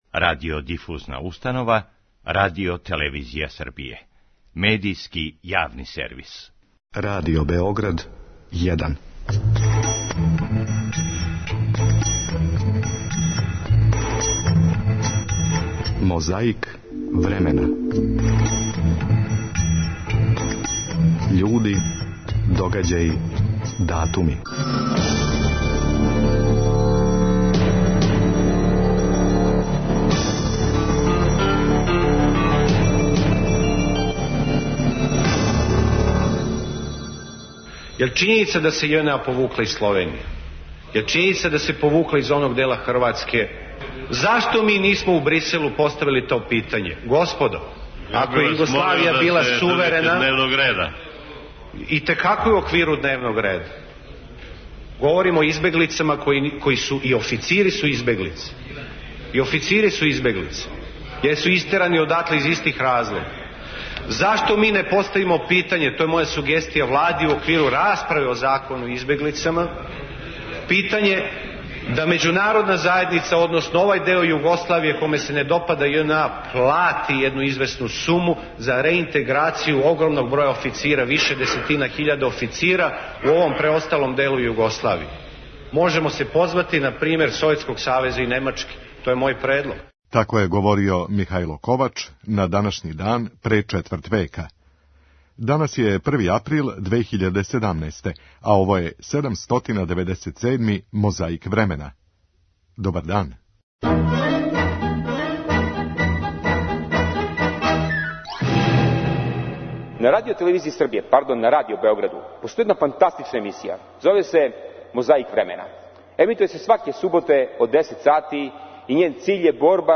Конференција за новинаре поводом завршетка другог састанка шесторице републичких председника у Београду 4. априла 1991. године није била пријатна ни новинарима, а понајмање испитаницима. Историја је забележила шта се догодило, али шта су забележили микрофони?